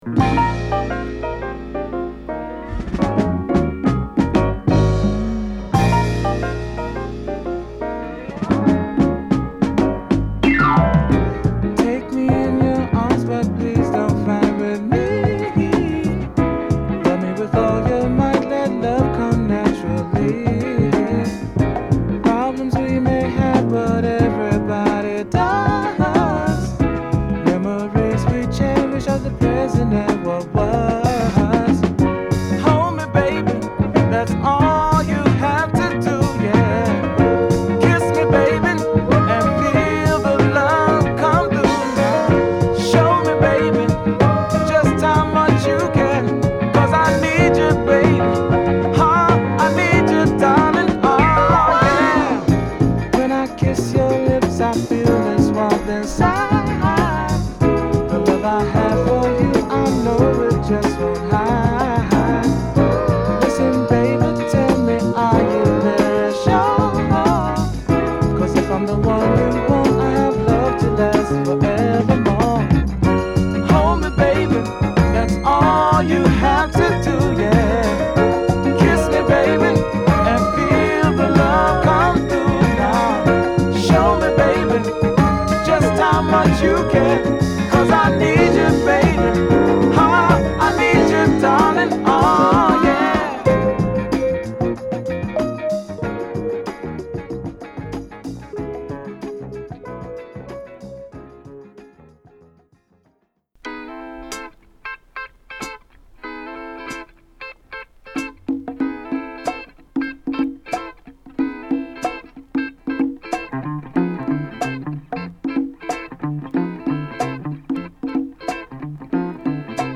初期のスピリチュアルな作風に比べてグッとソウルに歩み寄った1枚で